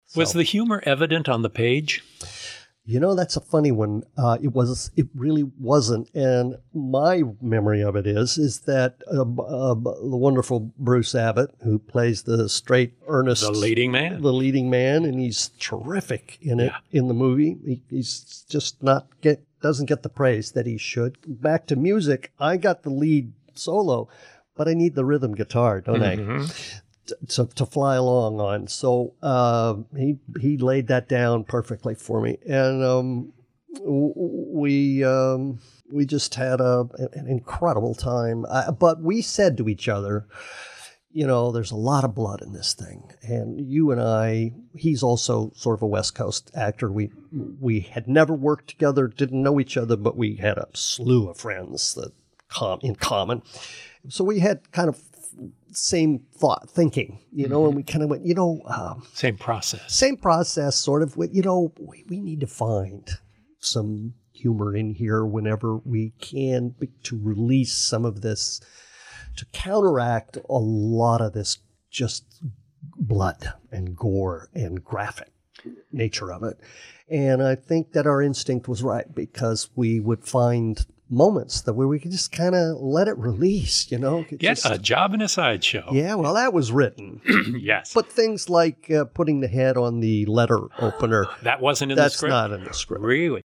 This week’s guest is none other than Dr. Herbert West himself, Jeffrey Combs, and he talks about Re-Animator‘s humorous sensibilities!